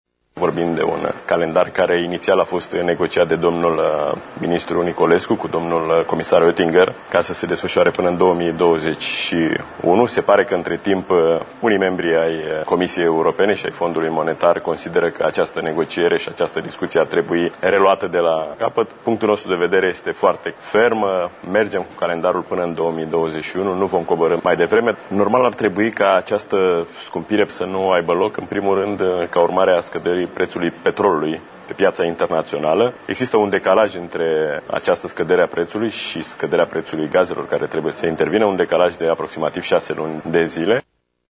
Invitat la Realitatea TV, Ministrul Finanţelor, Darius Vâlcov a dat de înţeles că există probleme asupra cărora cele două părţi nu au ajuns la un acord, printre care şi calendarul liberalizării preţurilor la gaze până în 2021, a cărui modificare este cerută de specialiştii FMI şi ai Comisiei Europene.